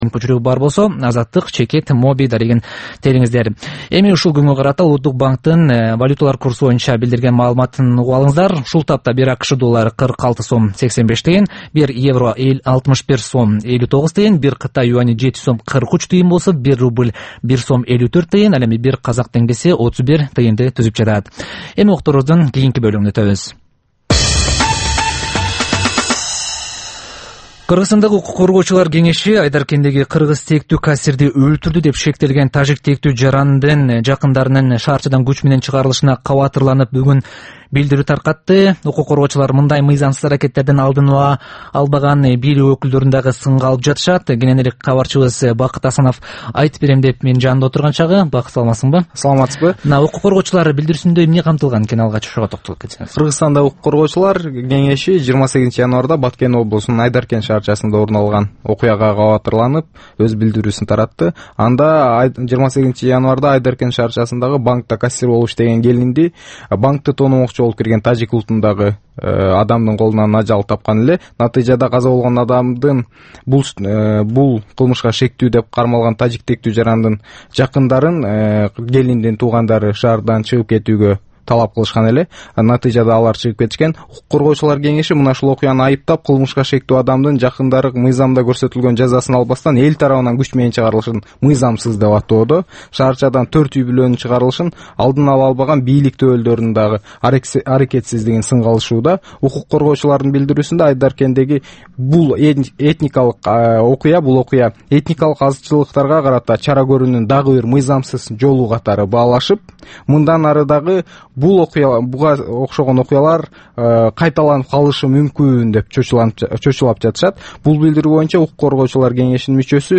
Бул түшкү үналгы берүү жергиликтүү жана эл аралык кабарлар, ар кыл орчун окуялар тууралуу репортаж, маек, талкуу, кыска баян жана башка оперативдүү берүүлөрдөн турат. "Азаттык үналгысынын" бул түш жаңы оогон учурдагы берүүсү Бишкек убакыты боюнча саат 13:00төн 13:30га чейин обого чыгарылат.